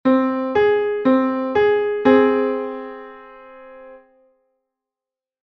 Made up of four whole-steps, the interval is one half-step wider than a perfect fifth.
minor-6.mp3